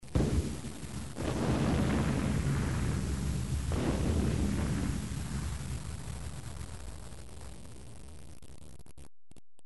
دانلود آهنگ بمب و موشک 7 از افکت صوتی حمل و نقل
دانلود صدای بمب و موشک 7 از ساعد نیوز با لینک مستقیم و کیفیت بالا
جلوه های صوتی